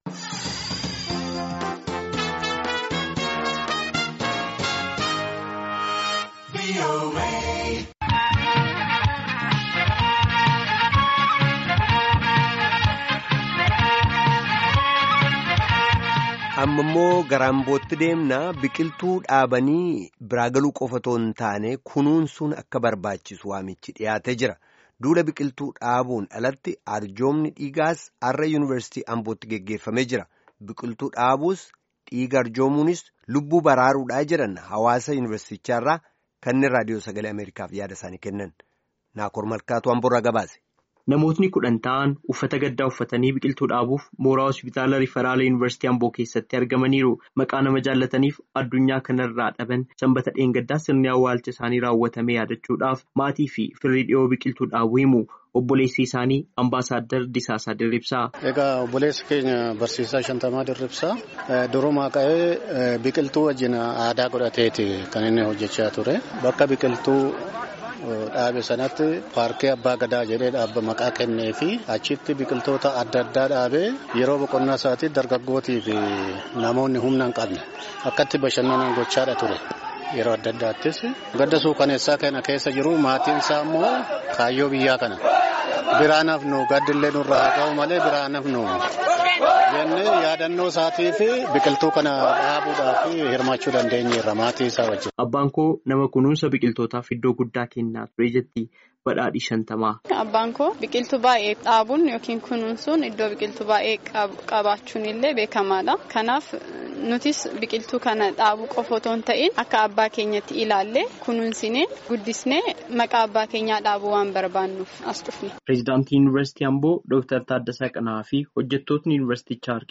Duula Biqiltuu dhaabuun alatti Arjoomni dhiigaas Yunivarsiitii Ambootti har’a geggeeffameera.
Biqiltuu dhaabuu fi Dhiiga arjoomuun lubbuu baraaruudha jedhan hawaasni Yunivarsiitichaa VOAtti yaada isaanii kennatan hagi tokko.